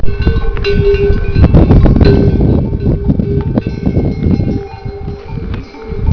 A fully loaded yak goes by